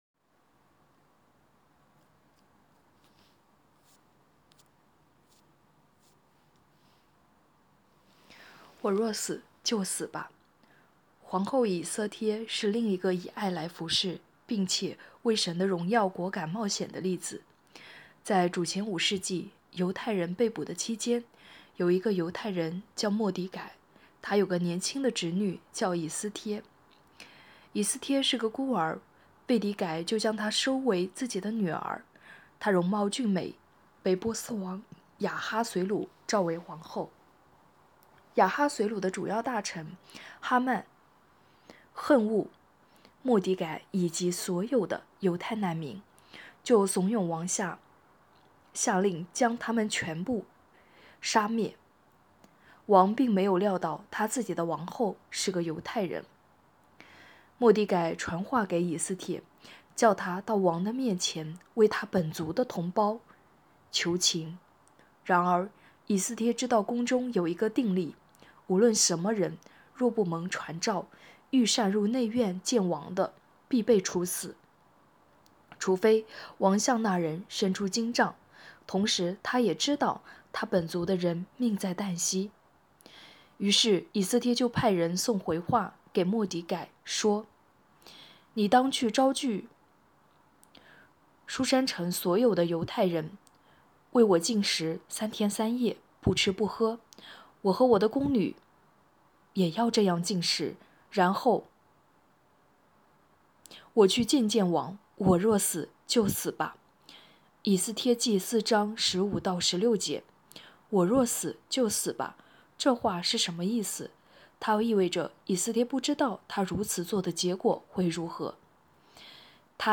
2024年1月26日 “伴你读书”，正在为您朗读：《活出热情》 欢迎点击下方音频聆听朗读内容 https